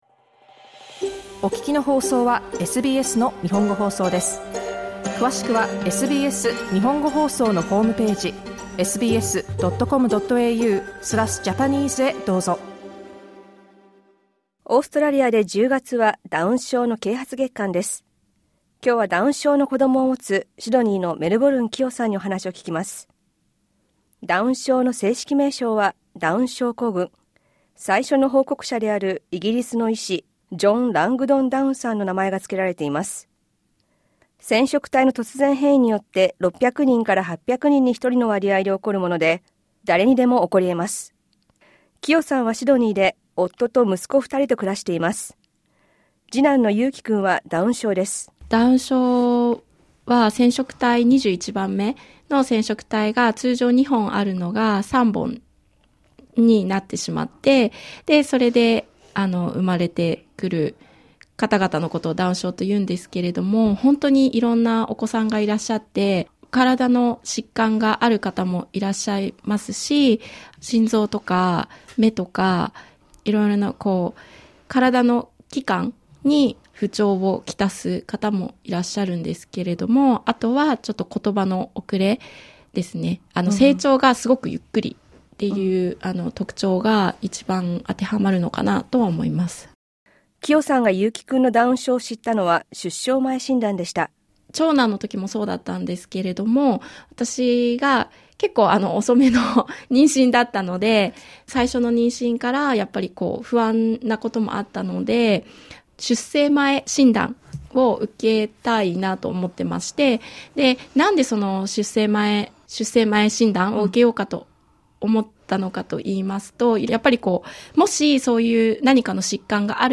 インタビューでは、診断を受けた後の気持ちや夫との話し合い、２人育児、そして10月のダウン症啓発月間を迎えて思うことなどを聞きました。